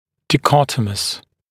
[dɪˈkɔtəməs][диˈкотэмэс]раздельный, дихотомический